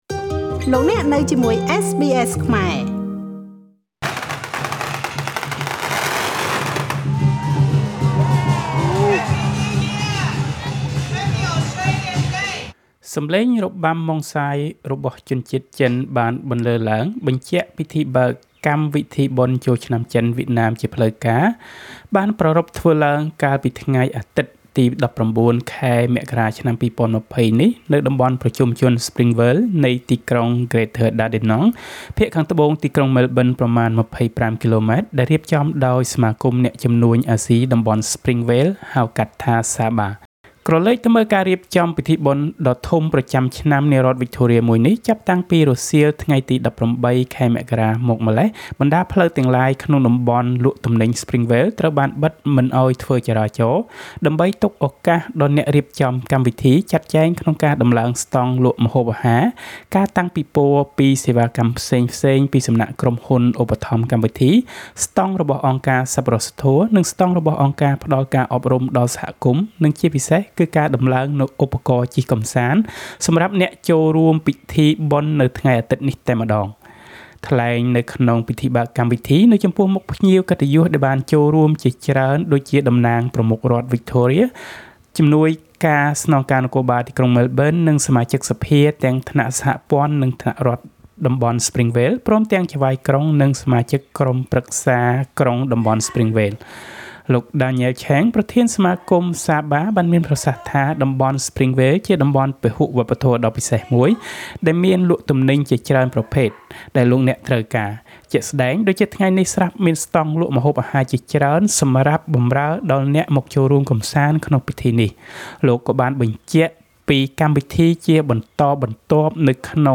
សំឡេងរបាំម៉ុងសាយរបស់ជនជាតិចិនបានបន្លើឡើងបញ្ជាក់ពិធីបើកកម្មវិធីបុណ្យចូលឆ្នាំចិនវៀតណាម ជាផ្លូវការបានប្រារព្ធធ្វើឡើងកាលពីថ្ងៃអាទិត្យទី១៩ ខែមករា ឆ្នាំ២០២០នេះ នៅតំបន់ប្រជុំជនស្រ្ពីងវេលនៃទីក្រុង Greater Dandenong ភាគខាងត្បូងទីក្រុងម៉ែលប៊ិនប្រមាណ ២៥គីឡូម៉ែត្រ ដែលរៀបចំដោយសមាគមអ្នកជំនួញអាស៊ីតំបន់ស្រ្ពីងវ៉េលហៅកាត់ថា SABA។